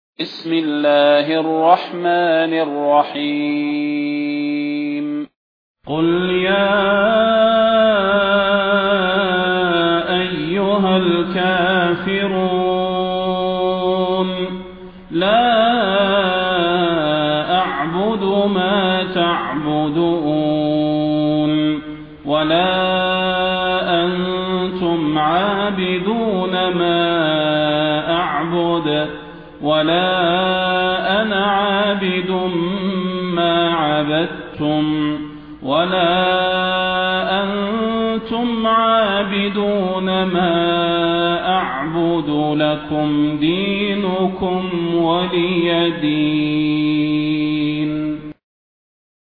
المكان: المسجد النبوي الشيخ: فضيلة الشيخ د. صلاح بن محمد البدير فضيلة الشيخ د. صلاح بن محمد البدير الكافرون